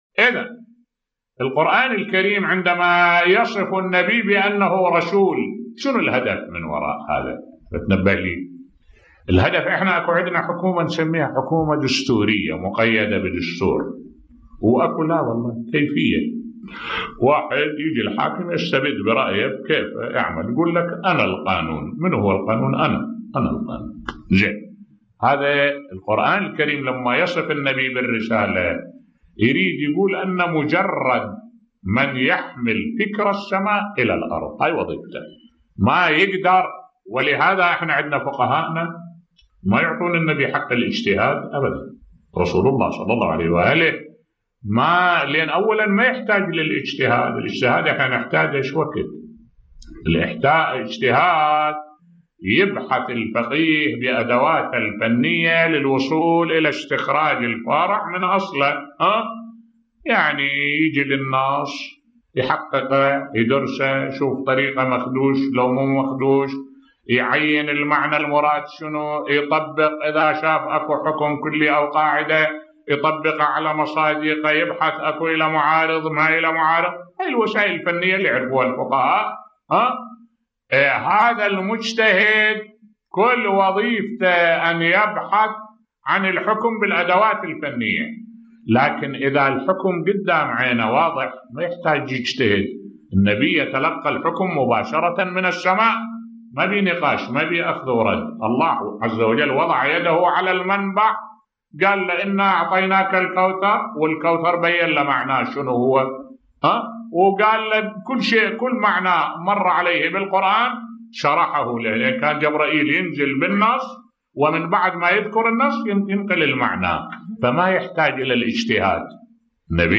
ملف صوتی رسول الله ليس له حق الاجتهاد بصوت الشيخ الدكتور أحمد الوائلي